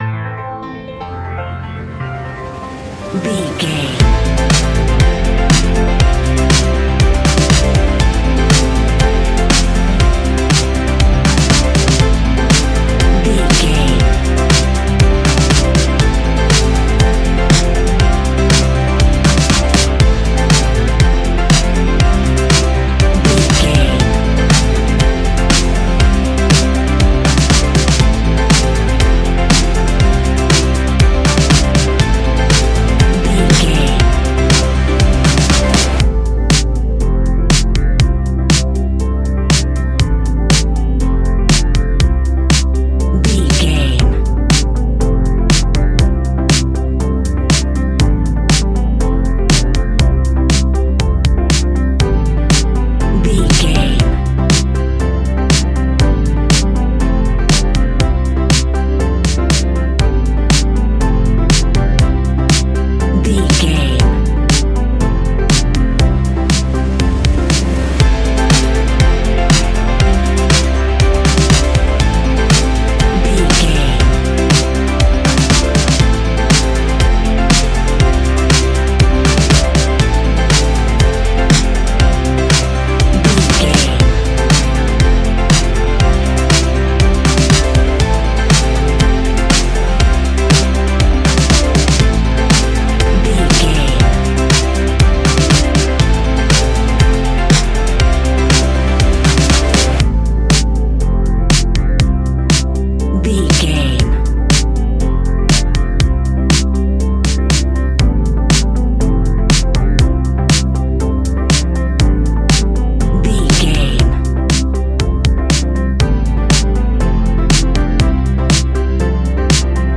Ionian/Major
ambient
electronic
new age
chill out
downtempo
synth
pads
drone
instrumentals